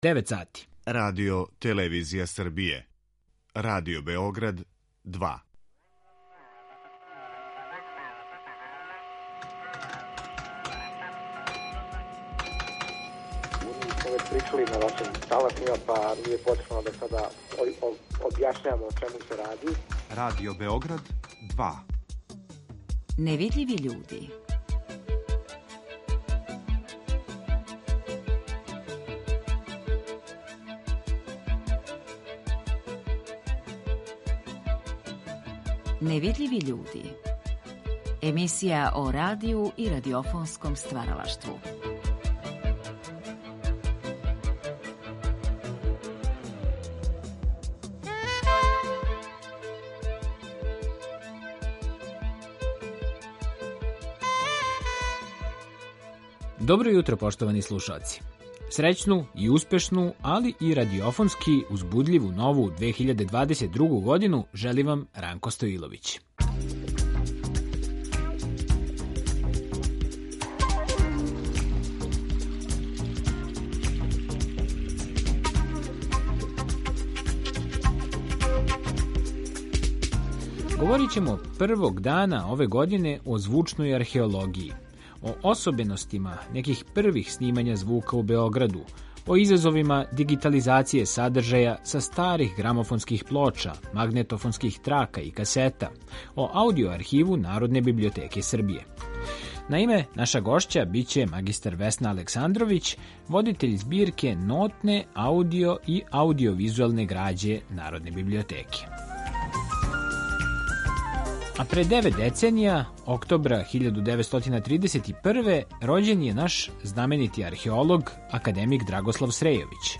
Моћи ћете да чујете и одломке из ове емисије у којој је Драгослав Срејовић подробно говорио о свом највећем открићу - Лепенском виру.